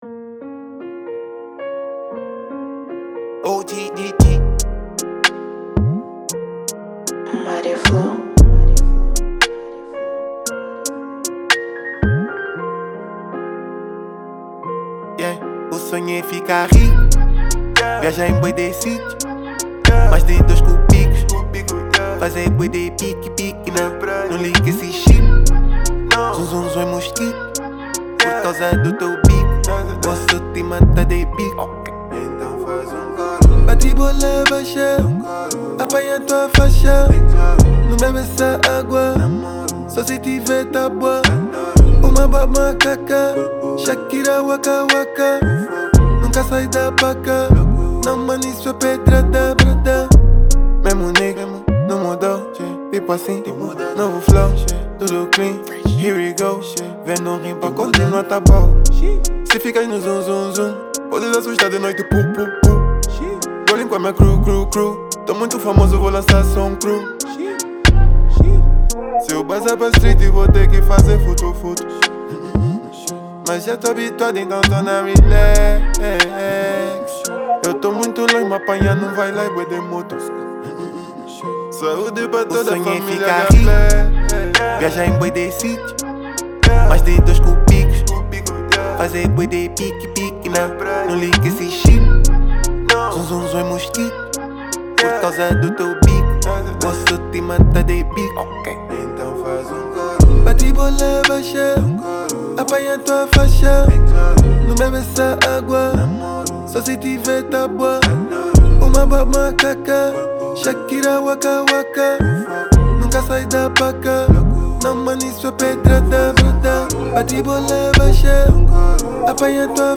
NacionalRap Angolano